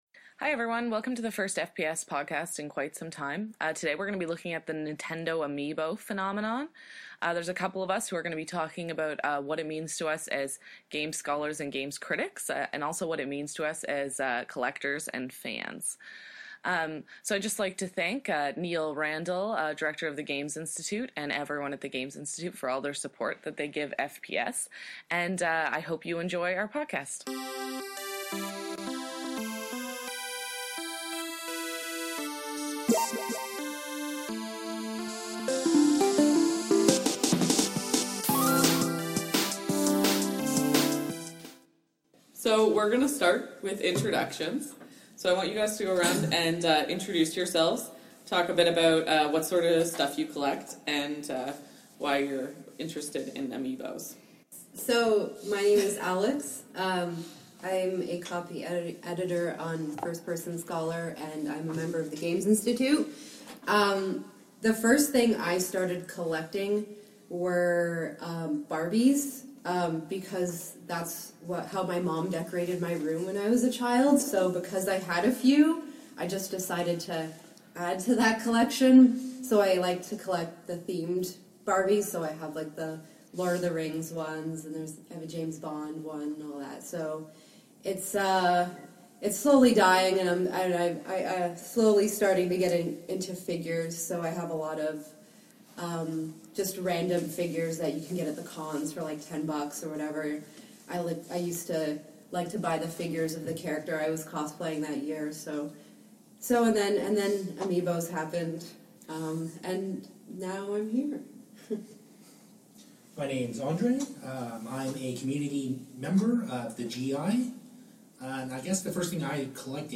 Five members of the Games Institute take a hard look at the Amiibo trend from a variety of angles including: historical materialism, fan exploitation, nostalgia, consumerism, fan cultures, and competing corporate strategies. A good mix of pontificating about Nintendo as a company and a culture and general nerding out.